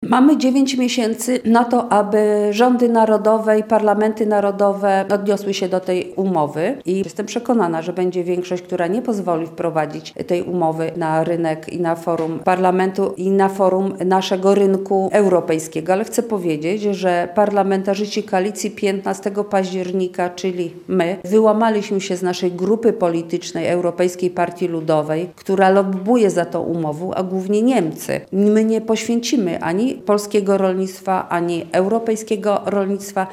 Koalicja 15 października i polski rząd będą stanowczo sprzeciwiać się umowie handlowej pomiędzy Unią Europejską, a krajami Mercosur – zadeklarowała w Lublinie europosłanka Marta Wcisło. Przeciwko umowie protestują między innymi polskie organizacje rolnicze, podnosząc, że zagraża ona europejskiemu i polskiemu rolnictwu.